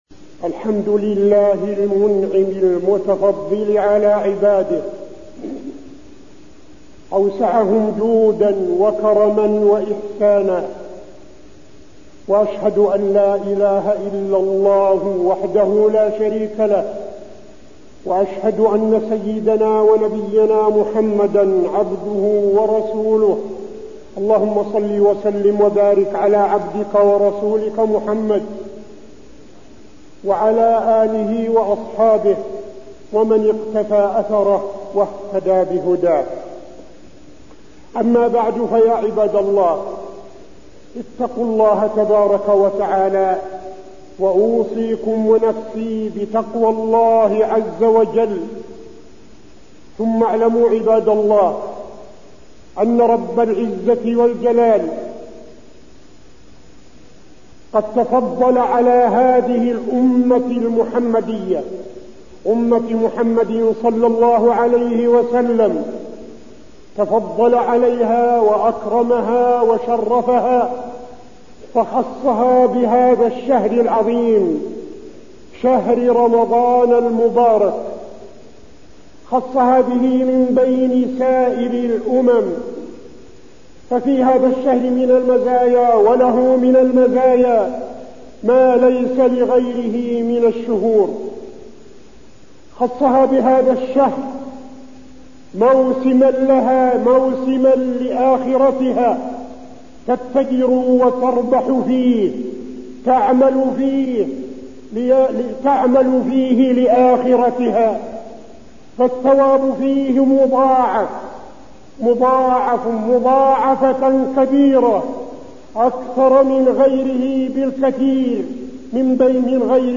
تاريخ النشر ٣ رمضان ١٤٠٧ هـ المكان: المسجد النبوي الشيخ: فضيلة الشيخ عبدالعزيز بن صالح فضيلة الشيخ عبدالعزيز بن صالح استغلال شهر رمضان